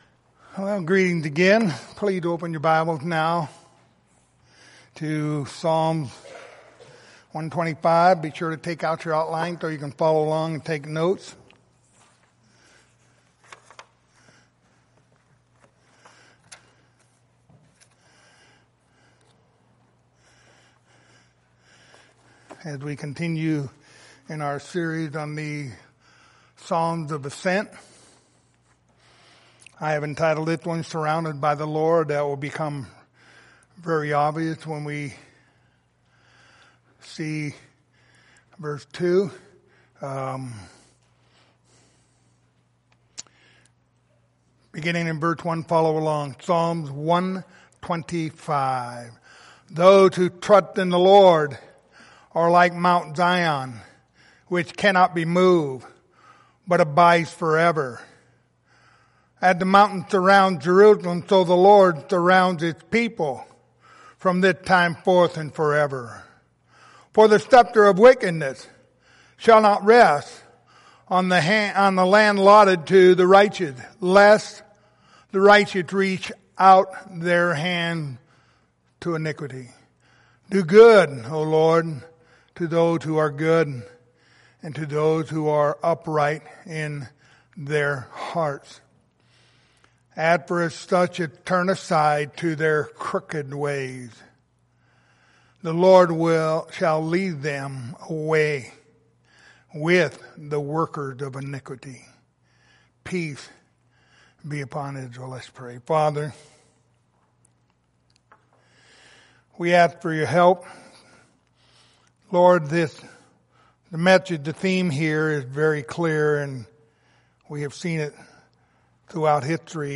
The book of Psalms Passage: Psalm 125:1-5 Service Type: Sunday Morning Topics